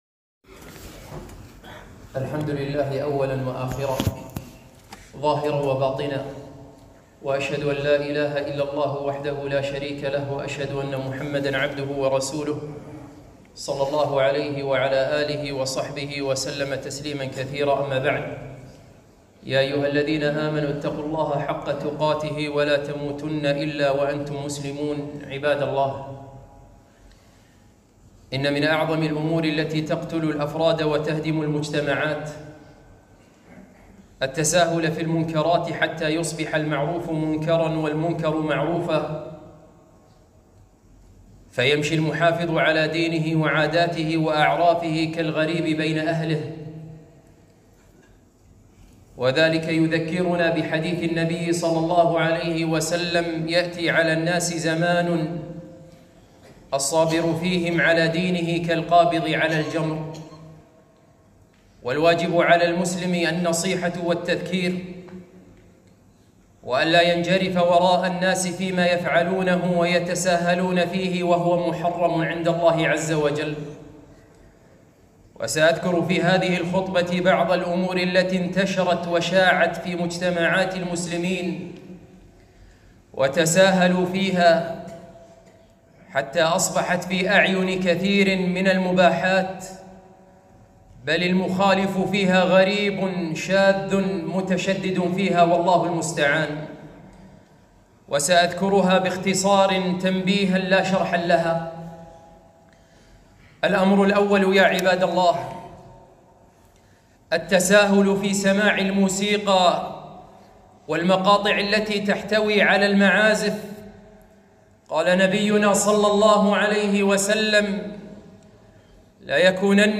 خطبة - عشرة محرمات كنت أظنها حلالاً!